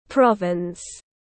Tỉnh tiếng anh gọi là province, phiên âm tiếng anh đọc là /ˈprɒv.ɪns/.
Province /ˈprɒv.ɪns/